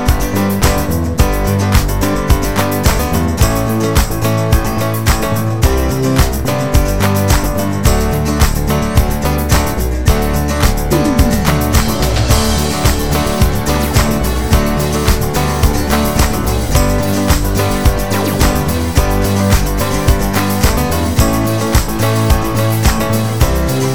No Guitars Pop (2000s) 4:13 Buy £1.50